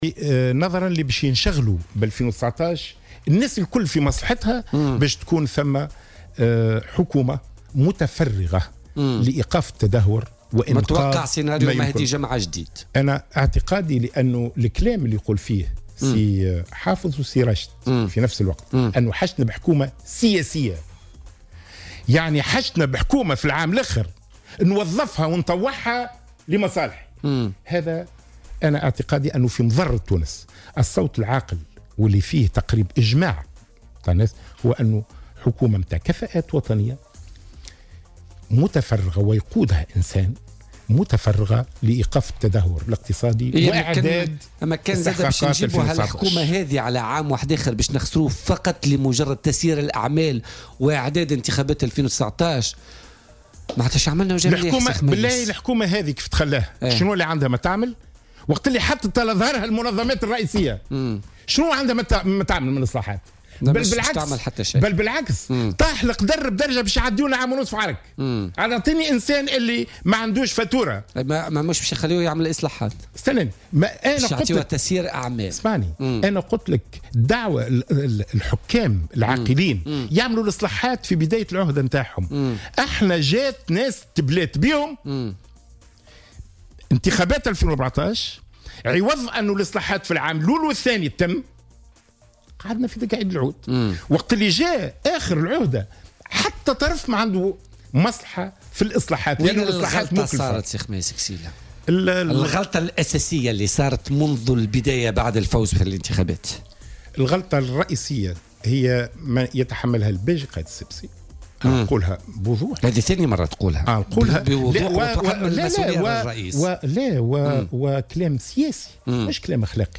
وأضاف ضيف "بوليتيكا" على "الجوهرة اف أم" ان الصوت العاقل وبالاجماع هو تشكيل حكومة كفاءات، منتقدا مطالبة راشد الغنوشي وحافظ قايد السبسي بحكومة سياسية.